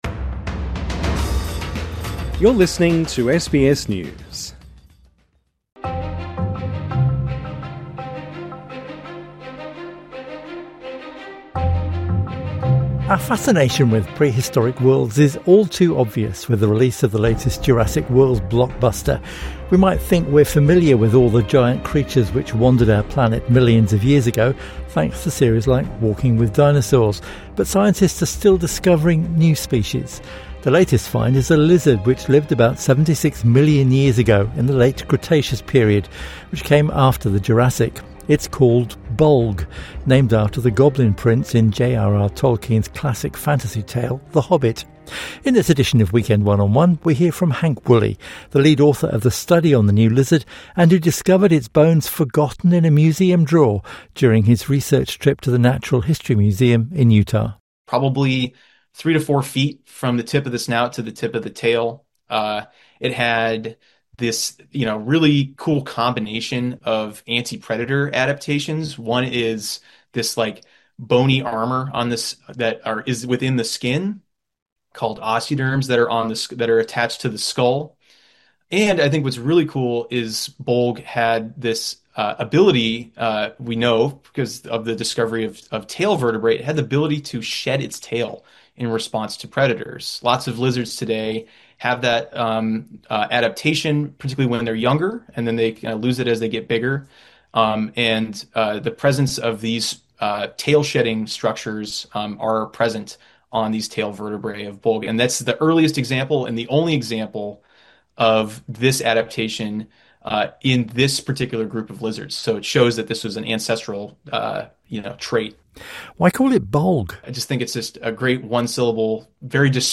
INTERVIEW: The new dinosaur found in a forgotten museum drawer 4:11